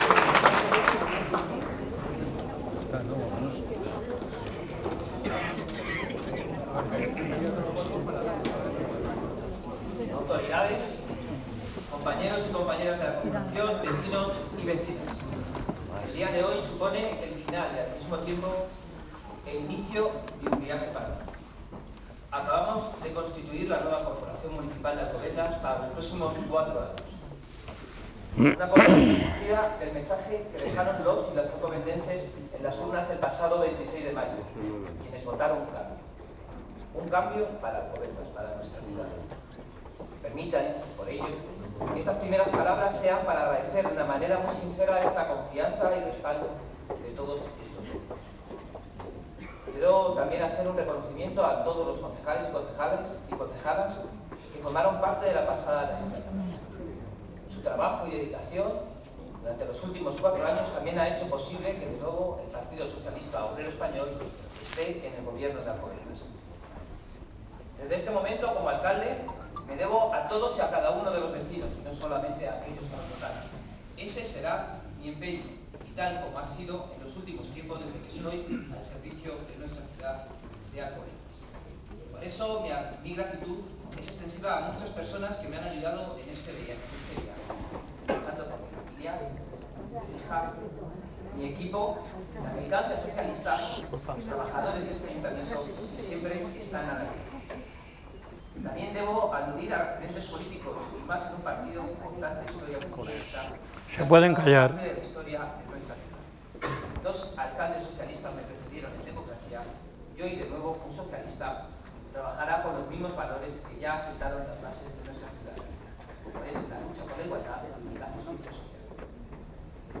El salón de actos del Ayuntamiento estaba a rebosar.
Discurso apertura Rafel Sanchez Acera